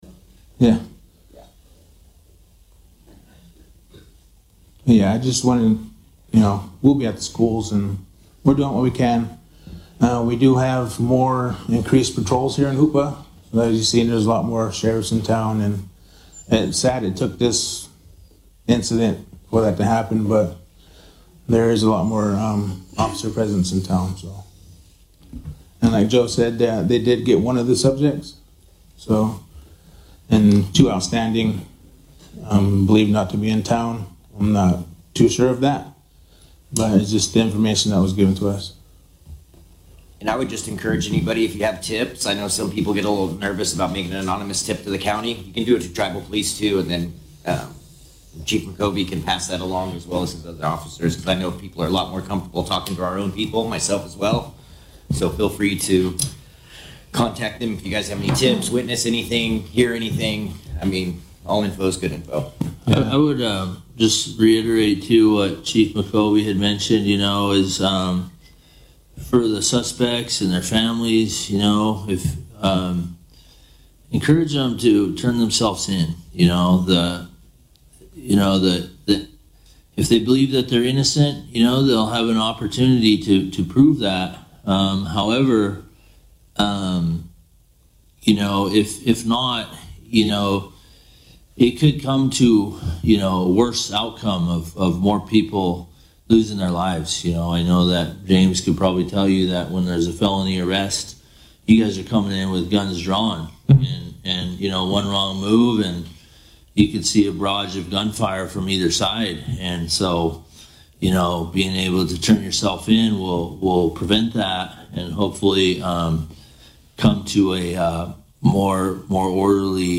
This audio recording is the second hour of 3 hours of the Hoopa Valley Tribal Council Emergency Meeting held at the Tribal Council Chambers on Wedsnesday, March 11, 2026. This audio has been slightly edited to delete the audio of the recorded steps heard as people walked to the microphone, or the sound of microphone adjustments, or any statements made far enough away that raising the playback volume could not clearly be understood.